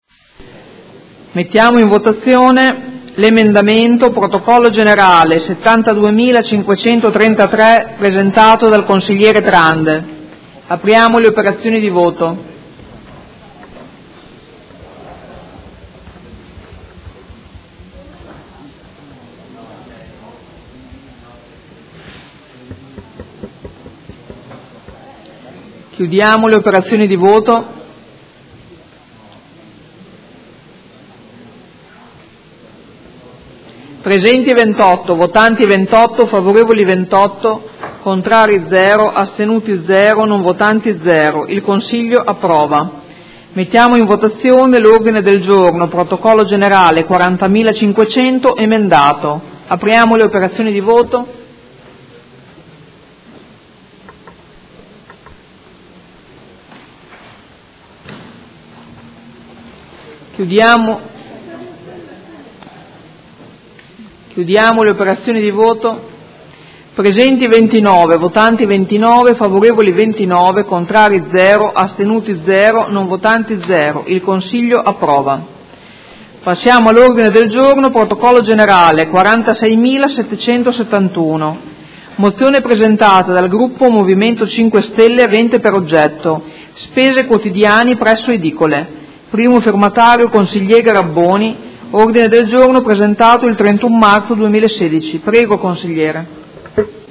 Presidente — Sito Audio Consiglio Comunale
Seduta del 12/05/2016.